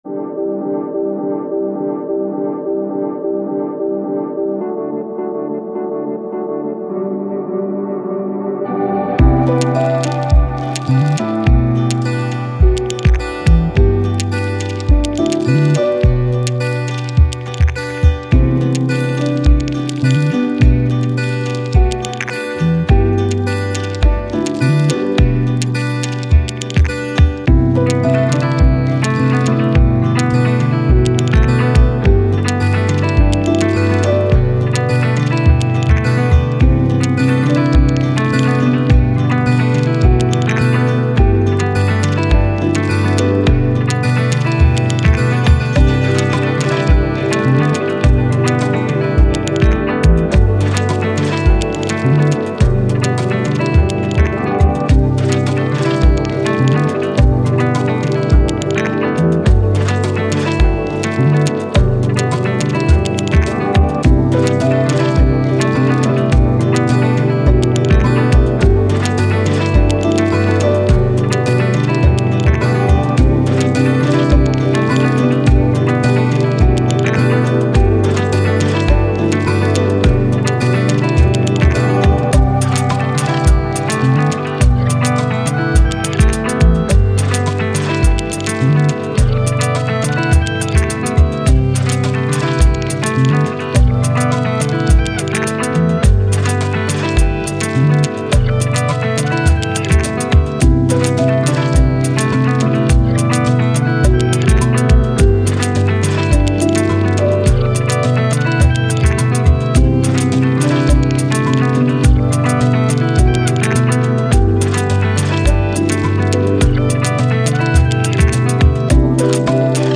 Downtempo, Ambient, Reverse, Landscapes, Dream